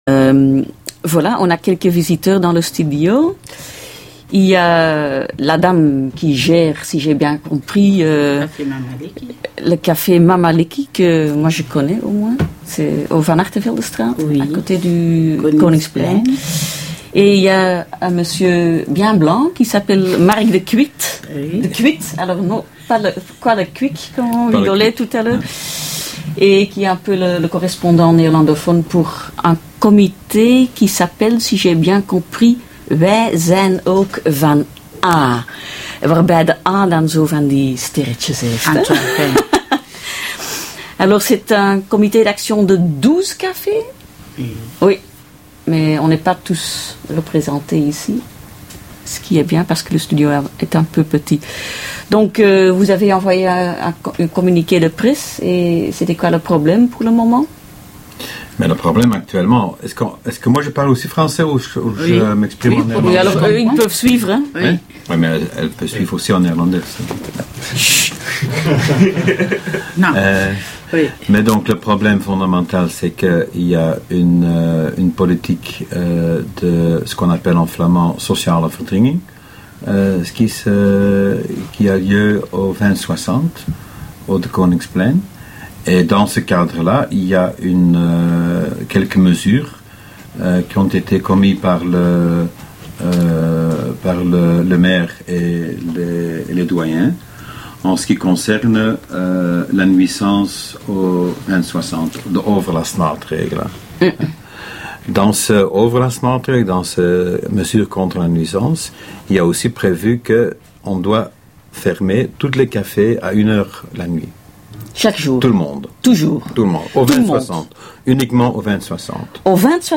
Eén van de twaalf cafés kwam naar onze studio met de heer die het comité in vlot Nederlands ondersteunt.